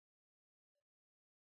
Аудиокнига 365 дней немецкого. Тетрадь четвертая.